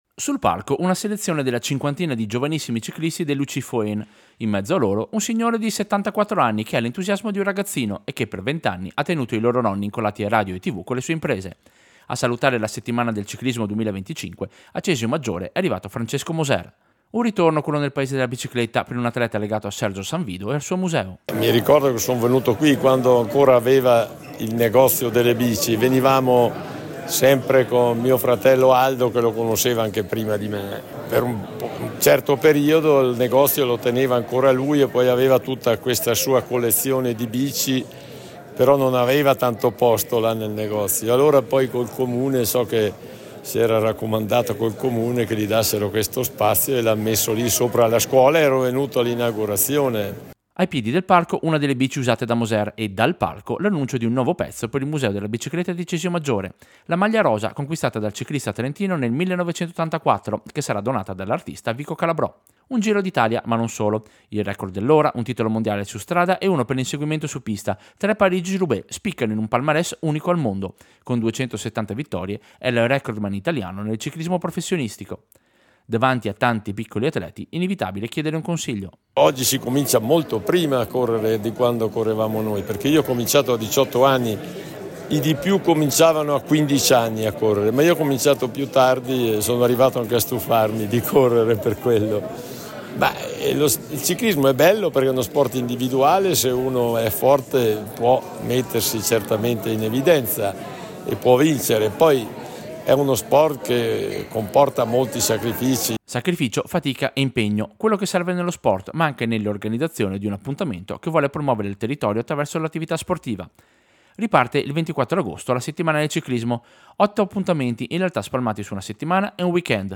Servizio-Settimana-ciclismo-Cesio-2025.mp3